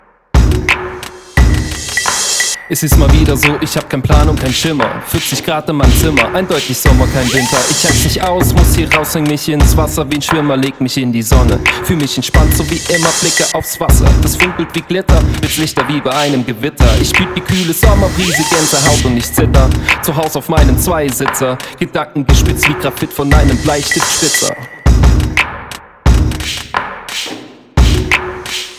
Im Mix mit nem Beat drunter fällt das meiner Meinung nach nicht mehr auf: Anhänge RapTest.mp3 RapTest.mp3 788,6 KB · Aufrufe: 599
Fand ich nicht, habe das File mit dem AT 2035 ebenfalls sehr stark kompriniert. Im Mix mit nem Beat drunter fällt das meiner Meinung nach nicht mehr auf: